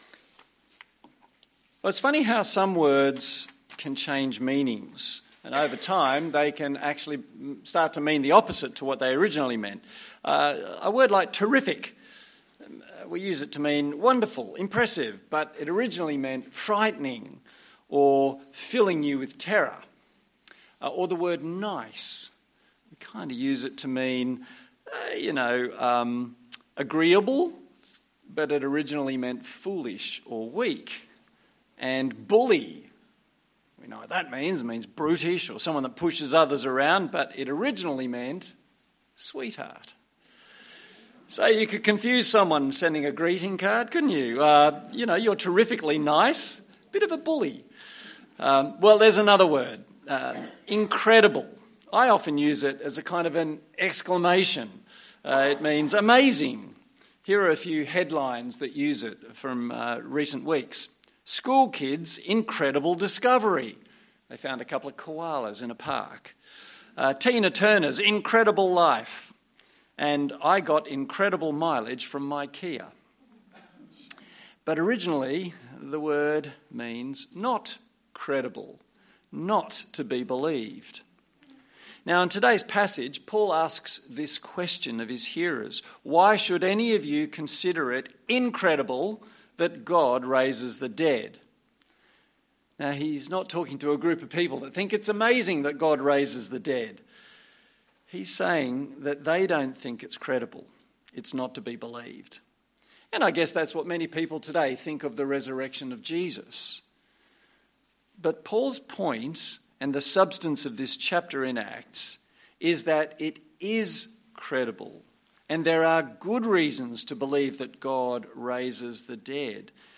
Acts Passage: Acts 26:1-32 Service Type: Sunday Morning Download Files Notes Topics